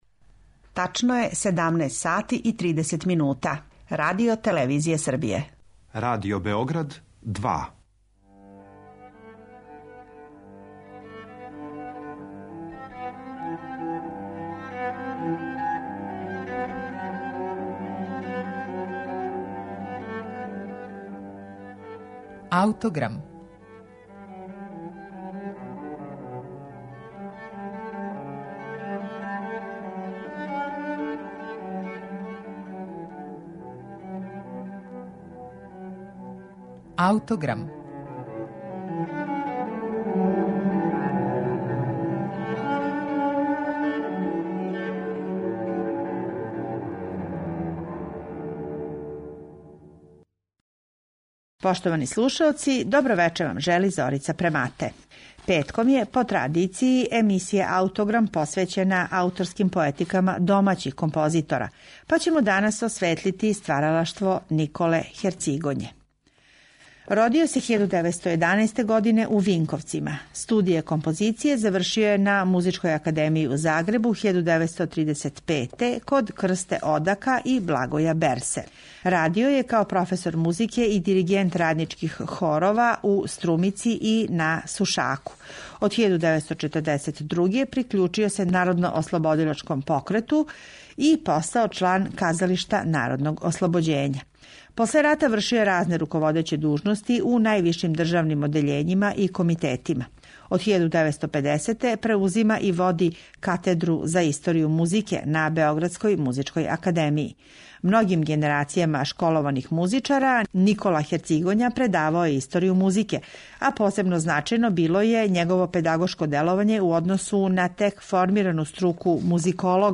архивске снимке песама са оркестром
бас